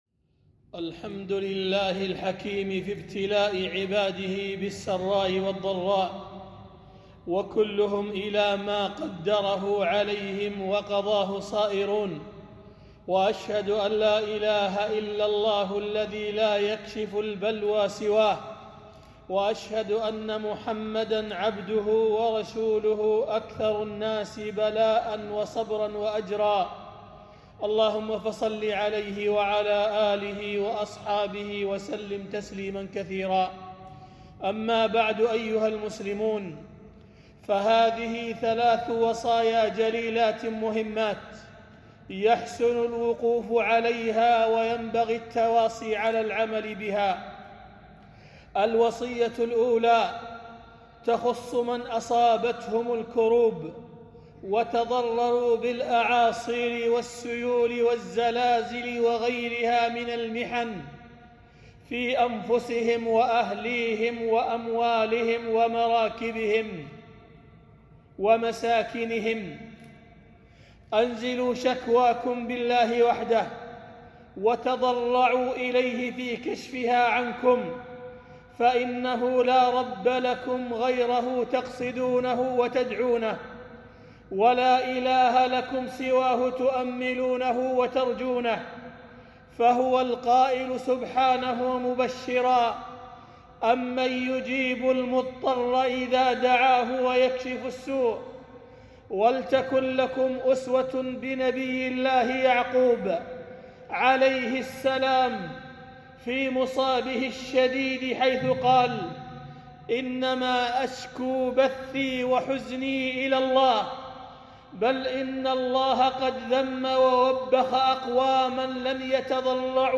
خطبة - وصايا لأهل الإسلام أوقات الكروب من زلازل وسيول وأعاصير